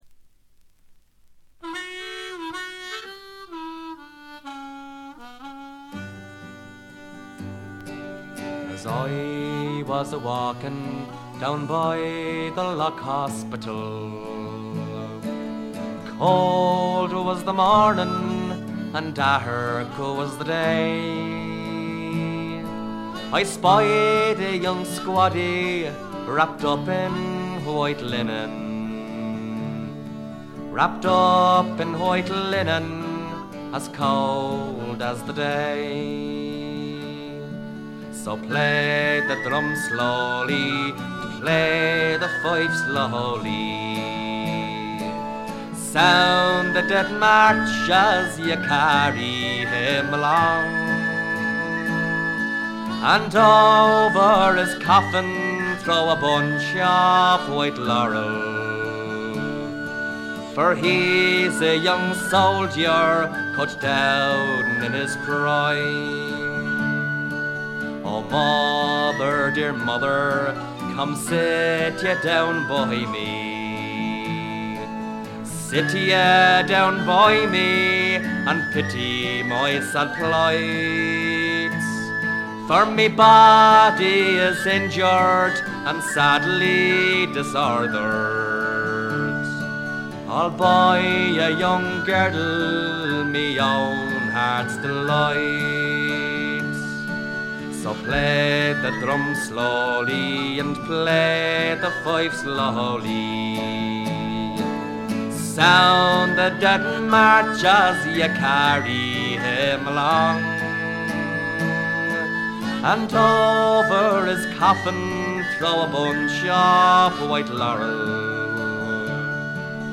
ほとんどノイズ感無し。
アイリッシュ・フォーク基本中の基本です。
中身は哀切なヴォイスが切々と迫る名盤。
試聴曲は現品からの取り込み音源です。